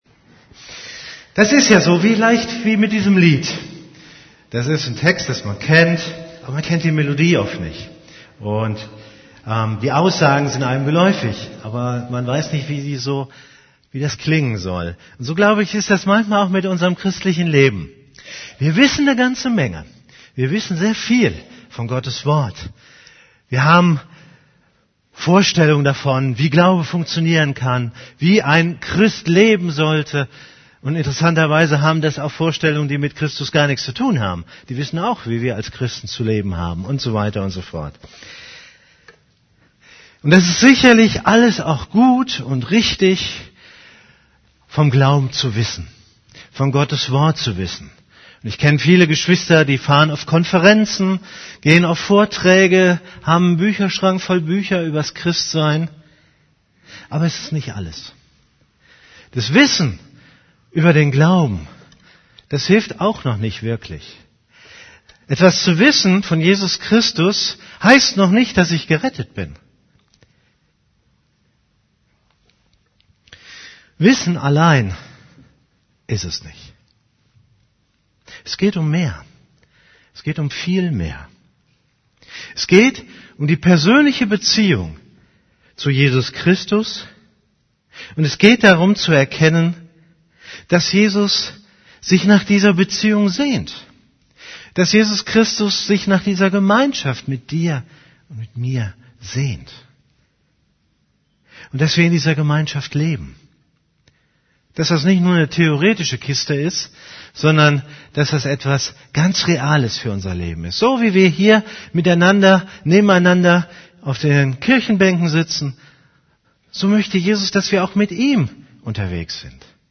> Übersicht Predigten Jesus möchte mit uns tiefe Gemeinschaft haben Predigt vom 02.